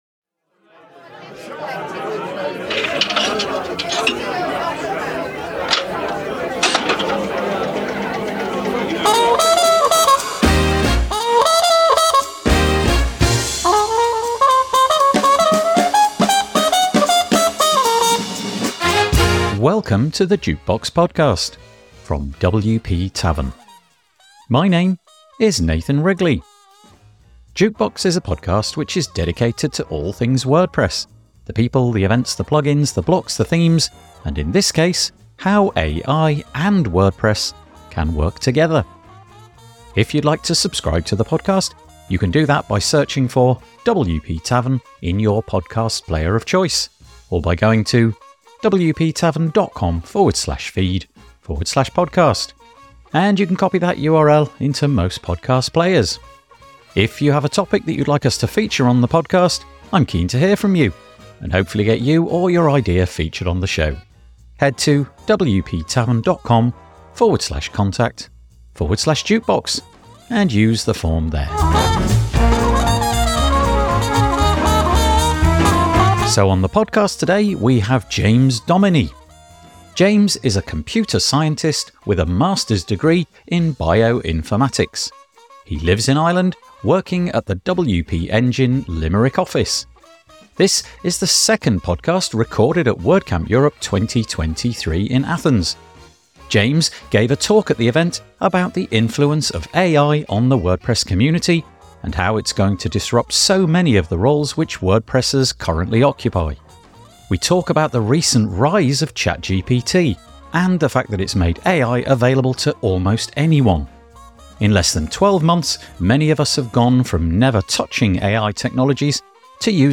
This is the second podcast recorded at WordCamp Europe 2023 in Athens.